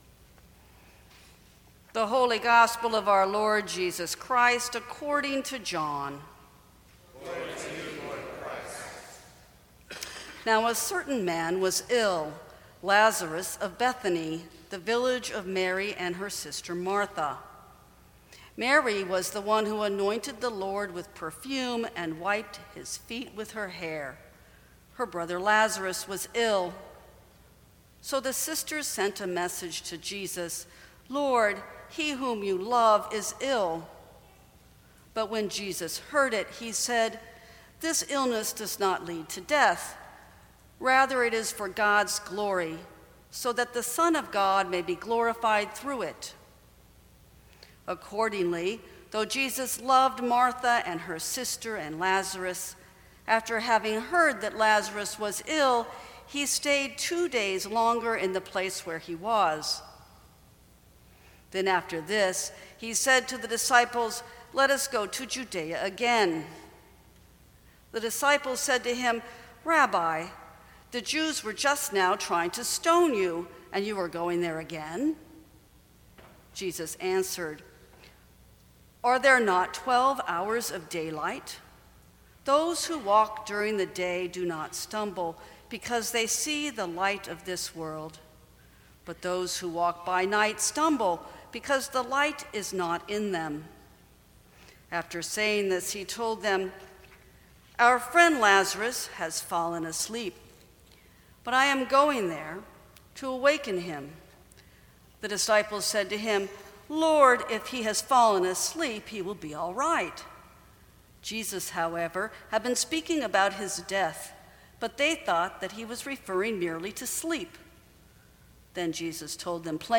Sermons from St. Cross Episcopal Church A Divine Act of Communication Apr 02 2017 | 00:21:18 1x 00:00 / 00:21:18 Subscribe Share Apple Podcasts Spotify Overcast RSS Feed Share Link Embed